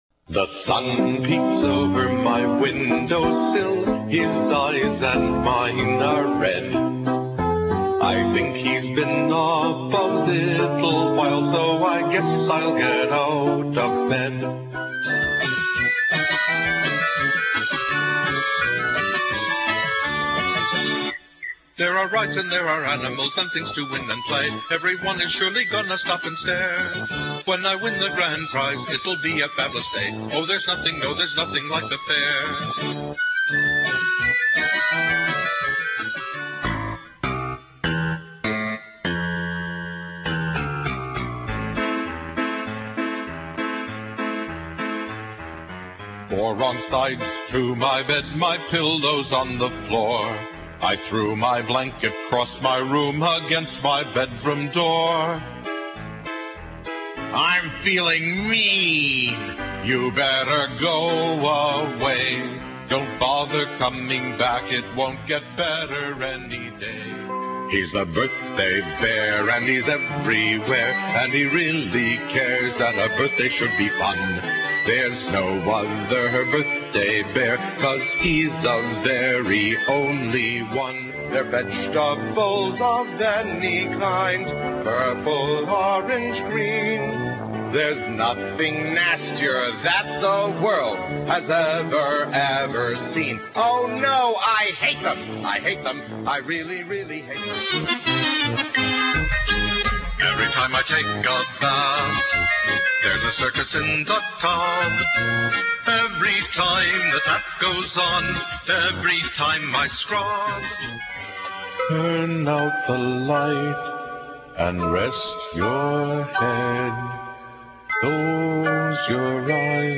Children's songs, parties, birthday.